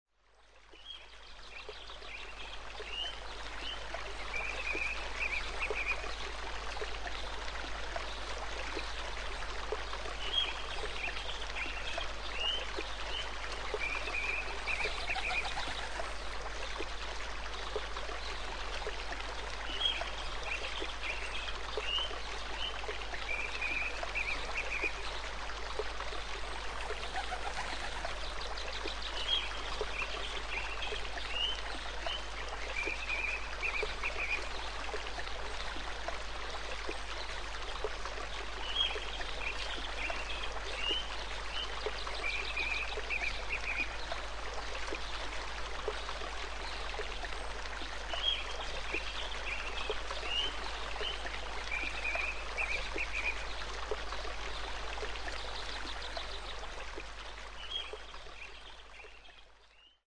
Babbling Brook
Category: Animals/Nature   Right: Personal